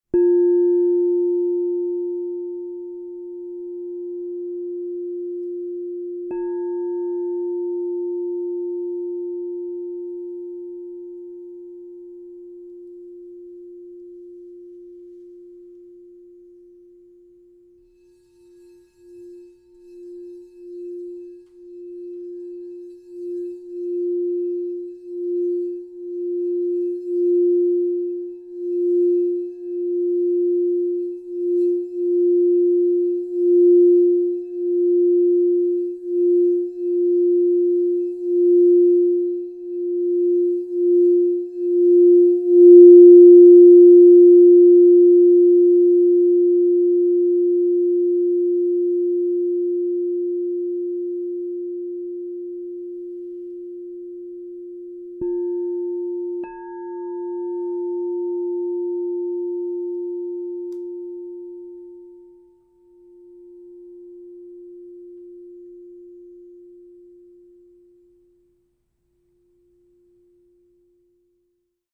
Lemurian Seed 9″ A +5 Crystal Tones singing bowl